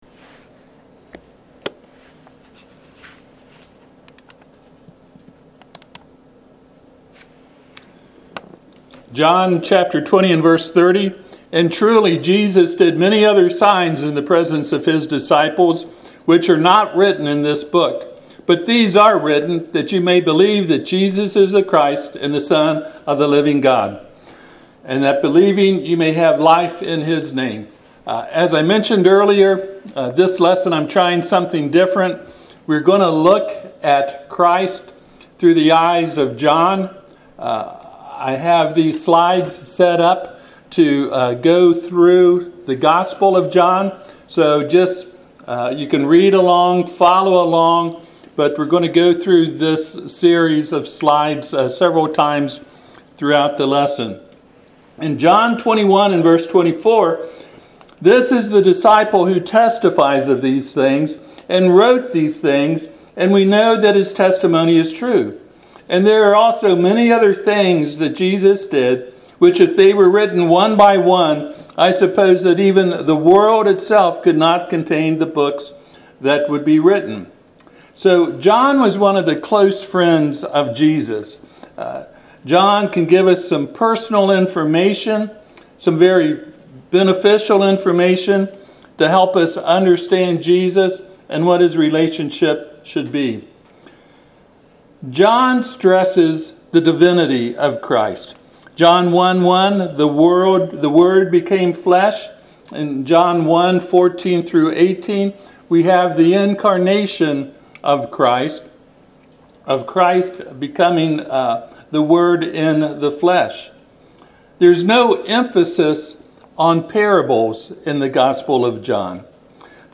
Passage: John 20:30-31 Service Type: Sunday AM